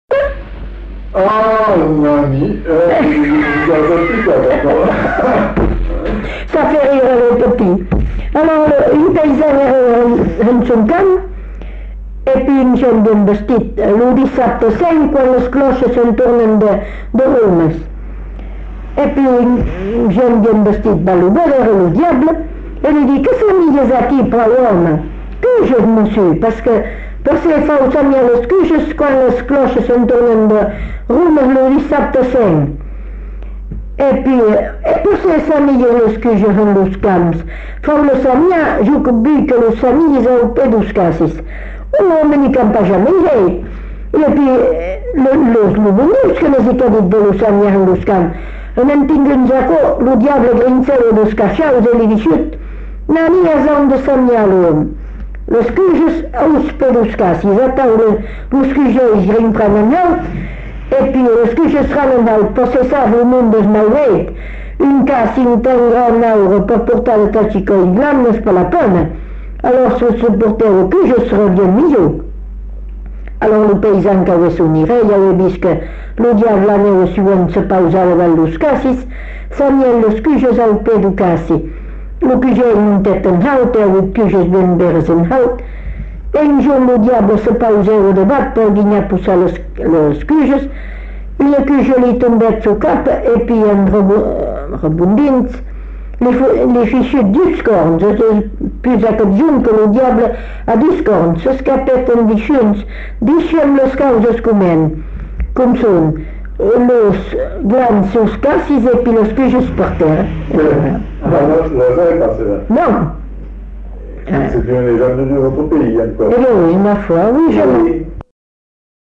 Lieu : Belin-Beliet
Genre : conte-légende-récit
Effectif : 1
Type de voix : voix de femme
Production du son : parlé